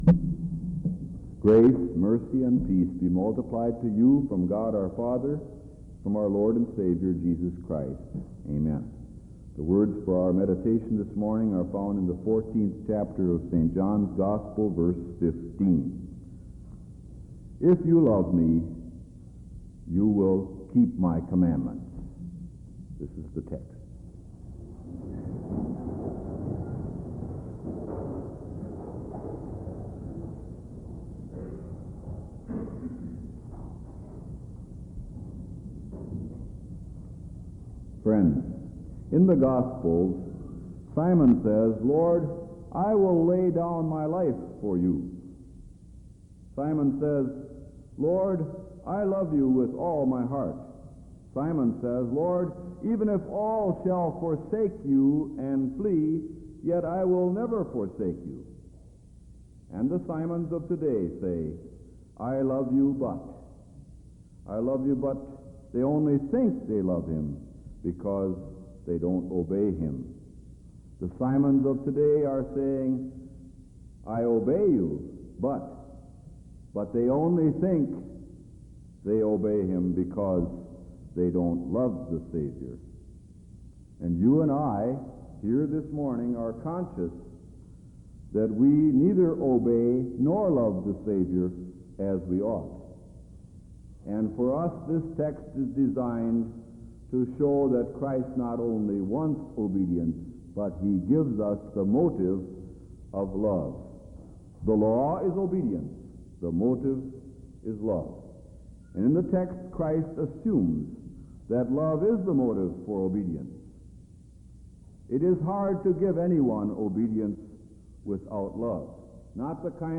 Chapel Sermons Academic Year 1976-1977 | Chapel Sermons | Concordia Seminary - Saint Louis